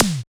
Q-TOM.wav